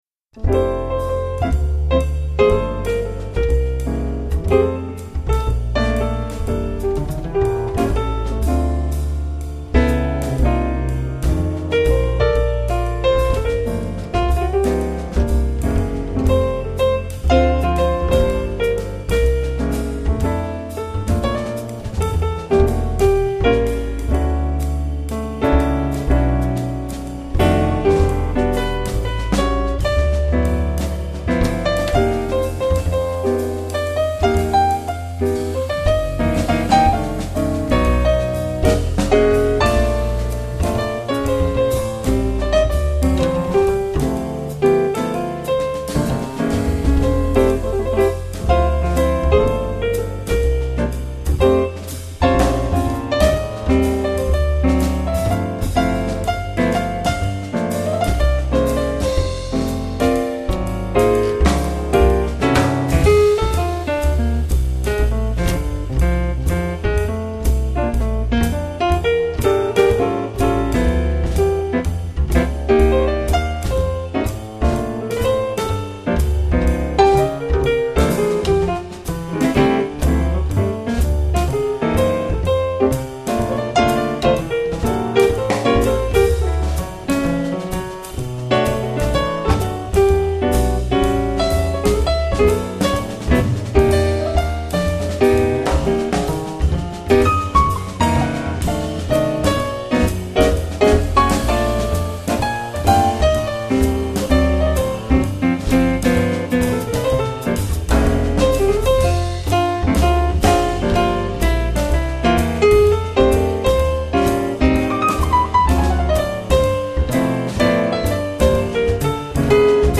jazz pianist